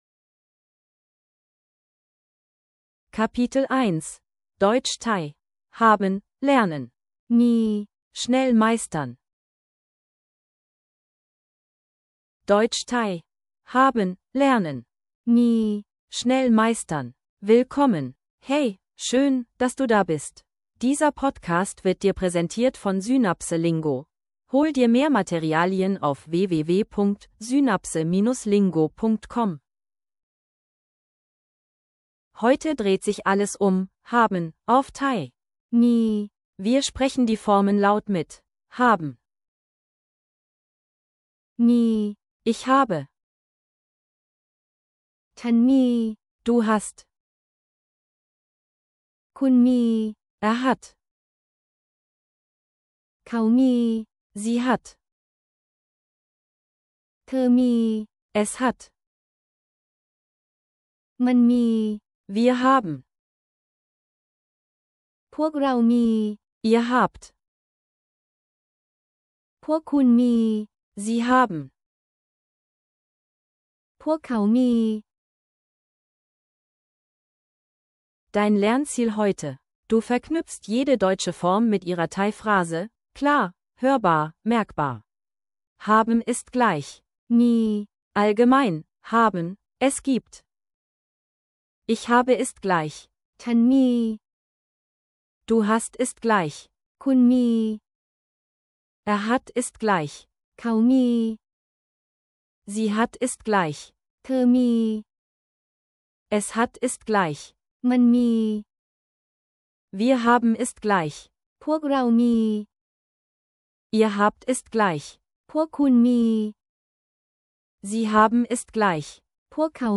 Audio zum Mitsprechen & Wiederholen